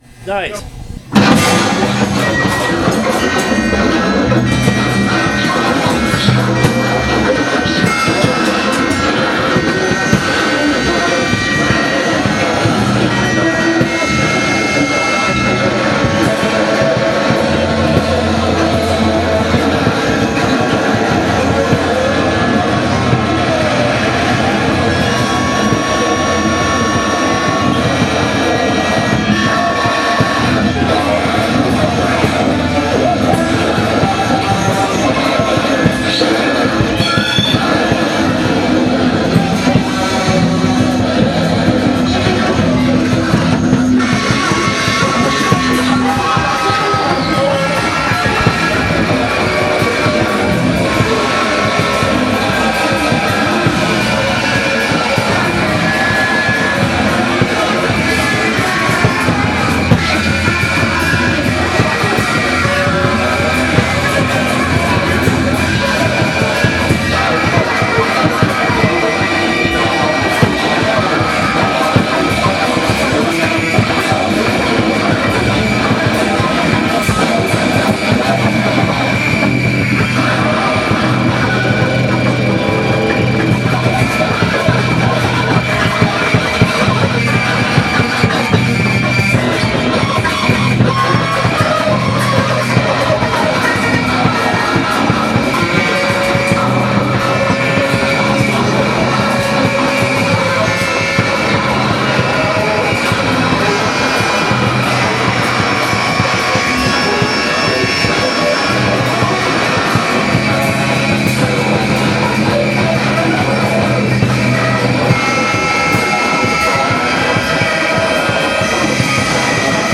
A segment of a performance
noise jazz quartet
which is an independent music space in Hudson, NY.